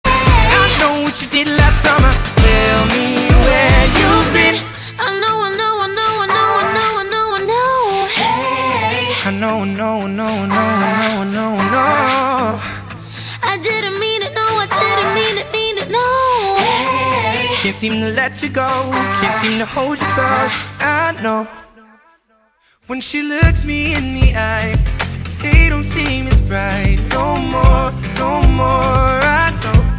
• Category Pop